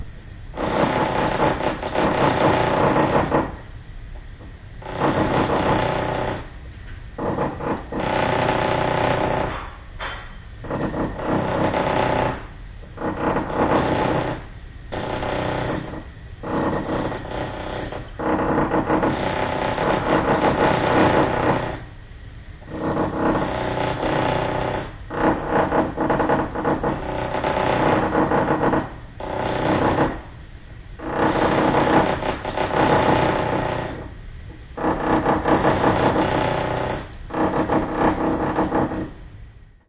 打破混凝土的前端装载机，手锤
描述：记录了一台前端装载机带着一个奇怪的尖头附件，通过推压混凝土直到其破裂。 在更远的地方，你还可以听到手提式锤子的敲击声。 在大约30英尺处录制。
标签： 混凝土 工业 手提钻
声道立体声